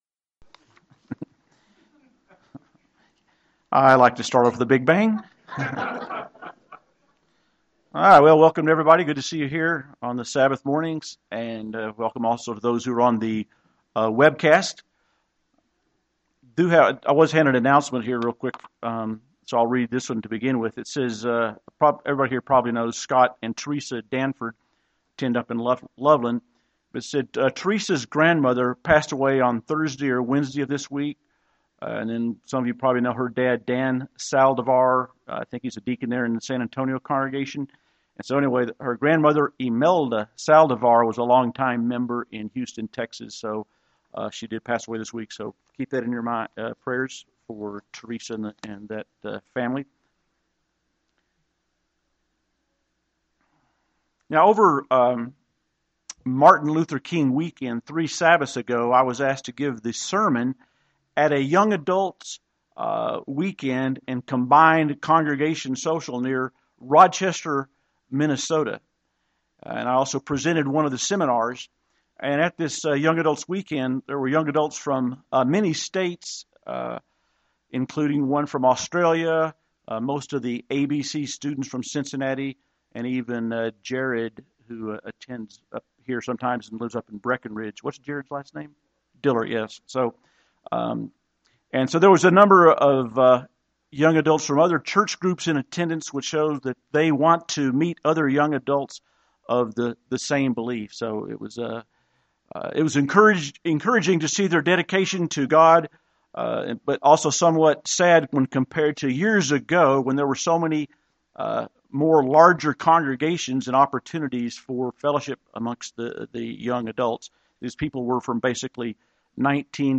Sermons
Given in Denver, CO